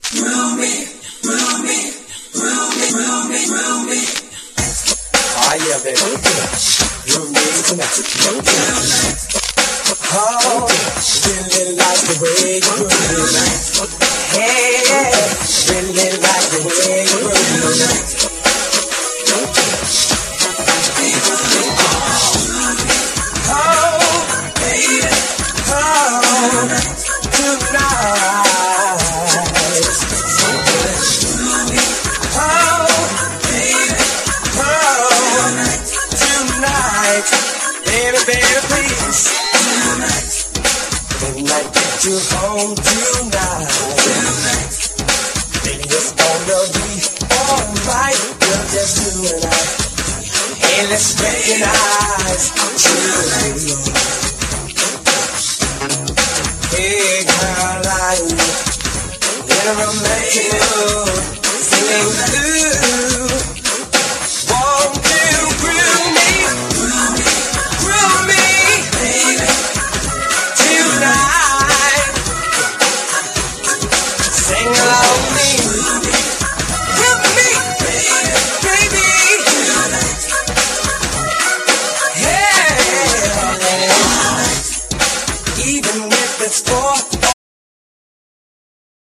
所によりノイズありますが、リスニング用としては問題く、中古盤として標準的なコンディション。
NEW JACK SWINGの代名詞。
あのはじけたような音がたまらない！